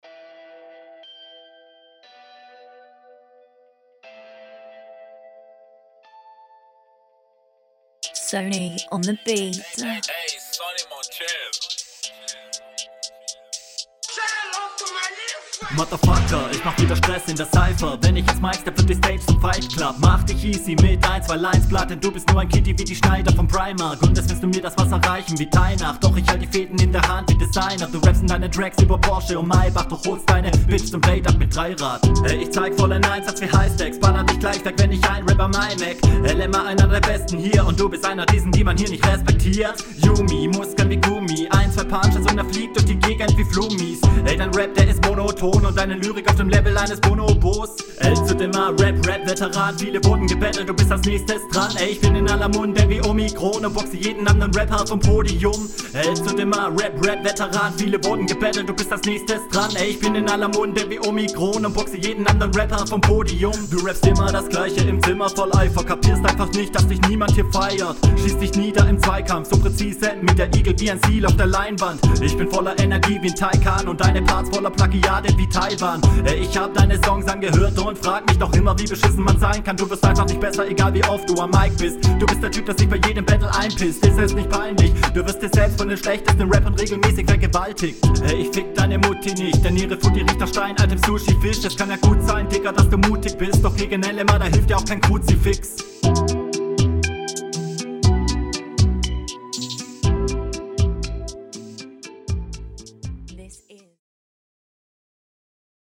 Ist prinzipiell schön über die Reime geflowt, aber leider auch nur darauf geschrieben, wodurch es …
Yo eig ganz cool, ich mag den Stimmeinsatz nicht so sehr, Mix ist auch n …